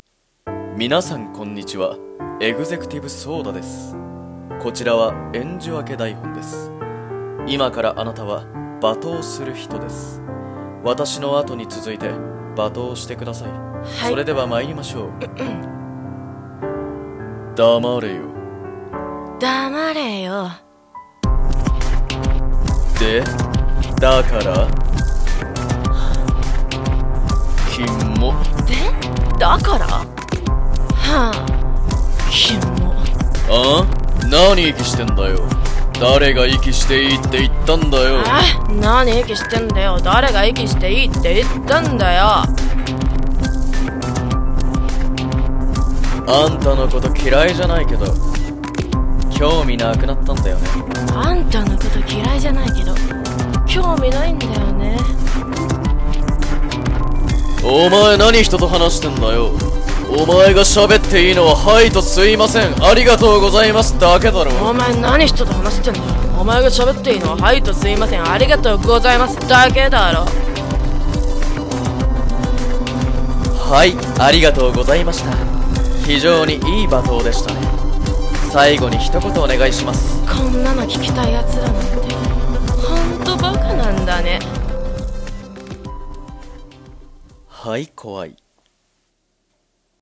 【声劇】演じ分け「罵倒」